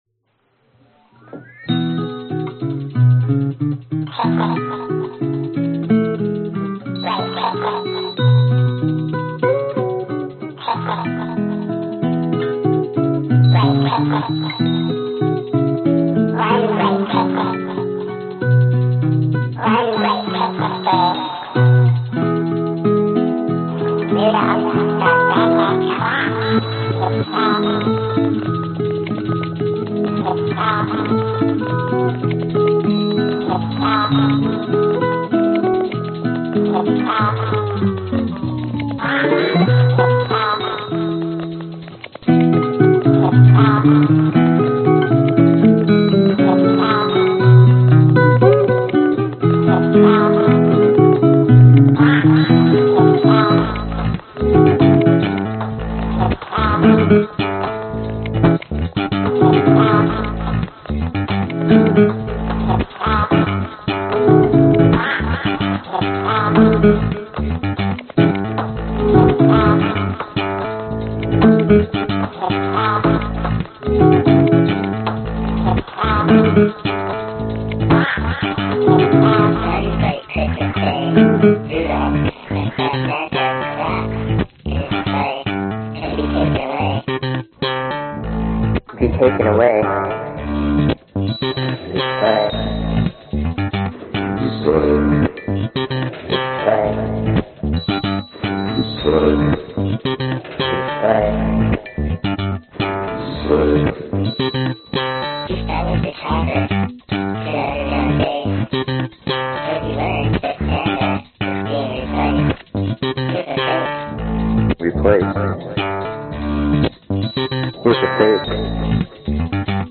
Tag: 圣诞 假日 驯鹿 蓝调 放克 实验性 男声 电子 口语 放克